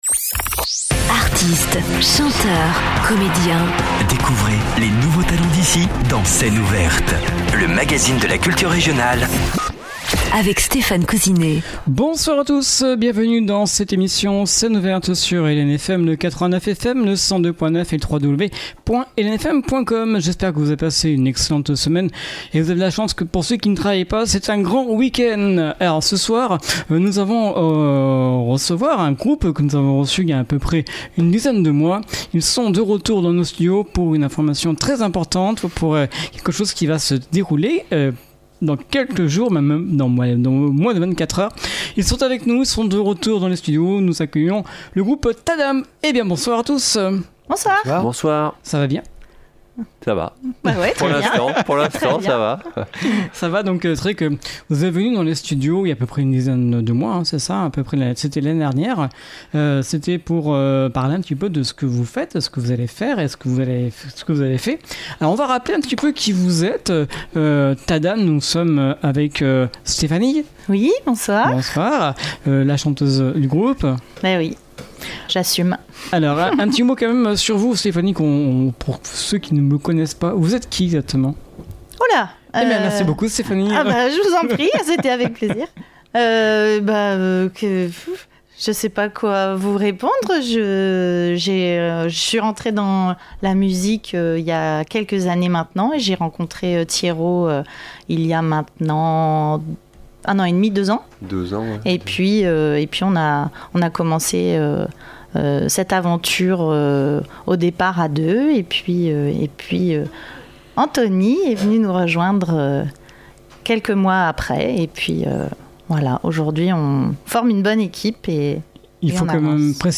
son concert composé de 16 chansons originales et françaises.